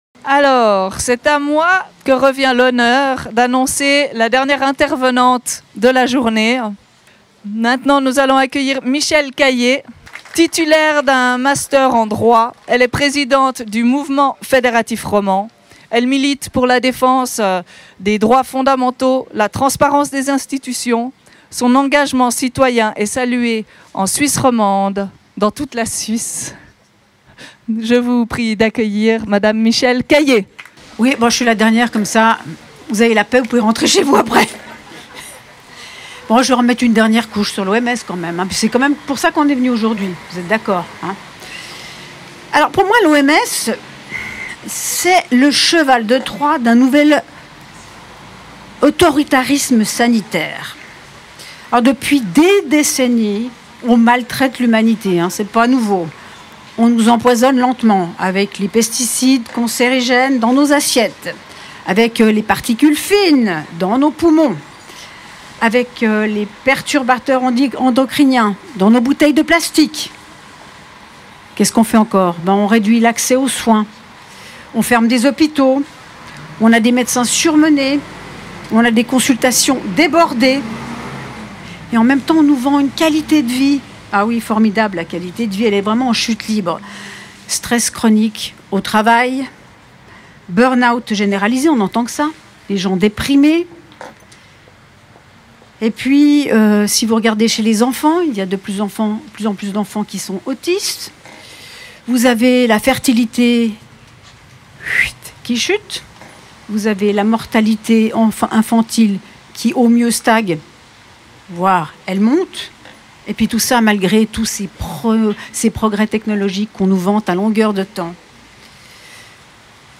Discours du 21 mai 2025, Place des Nations, Genève – Manifestation contre le traité de l’OMS: